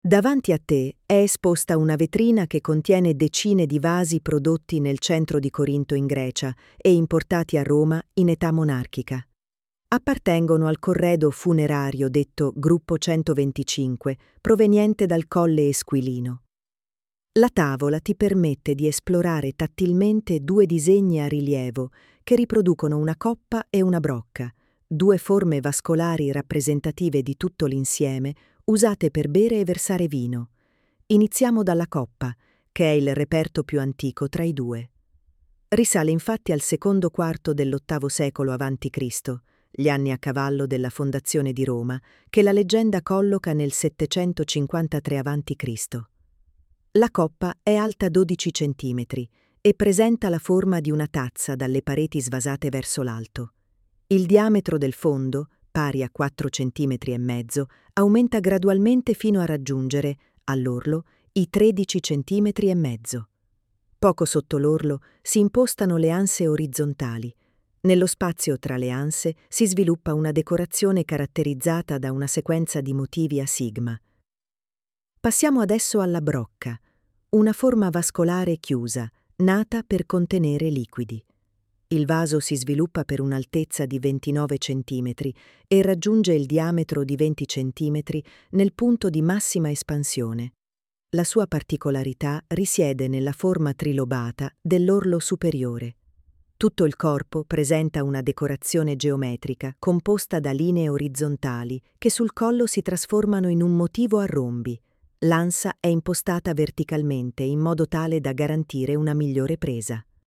•    9 AUDIODESCRIZIONI che accompagnano il visitatore nell’esplorazione delle opere, ognuna indicata da didascalia in Braille e guida audio, con il relativo testo, fruibile tramite QR code: